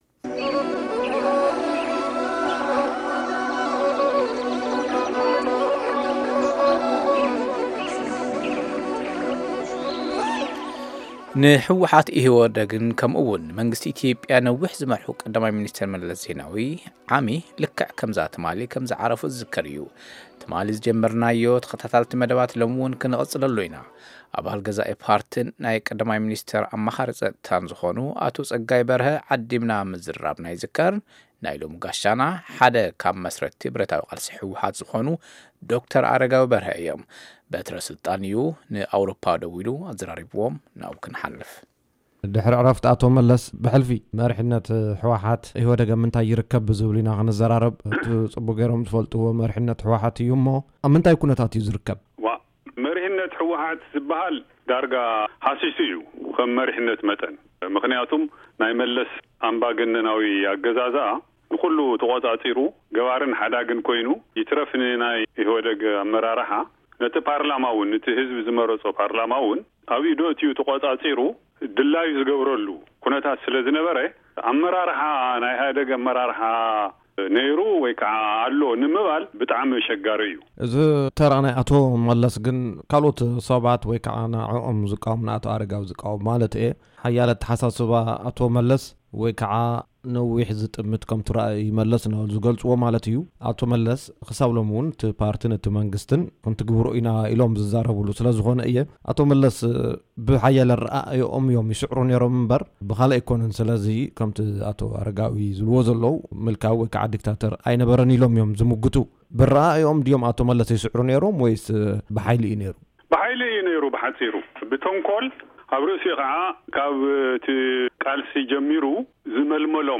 ’መሪሕነት ህወሓት ዳርጋ ሃሲሱ‘ዩ።ምኽንያቱ ናይ መለስ ኣምባገነናዊ ኣገዛዝኣ ንኩሉ ተቆፃፂሩ፣ገባርን ሓዳግን ኮይኑ፣ይትረፍ ንኣመራርሓ ኢህወዴግ ነቲ ህዝቢ ዝመረፆ ፓርላማ‘ውን ተቆፃፂሩ ድላዩ ዝገብረሉ ኩነታት‘ዩ ነይሩ‘ ይብሉ ዶ/ር ኣረጋዊ በርሀ። ኢትዮዽያ ሓደ ዓመት ድሕሪ ዕረፍቲ ቀ/ሚ መለስ ዜናዊ ኣብ ዝብል ተዓዲሞም ቃለ-ምልልስ ዝሃቡን መስራታይ ህወሓት ዝኾኑን ዶ/ር ኣረጋዊ(በሪሁ) በርሀ፣ልዕልነት ኣቶ መለስ ዜናዊ ዝተረጋገፀ ብሓይልን ብተንኮልን‘ዩ...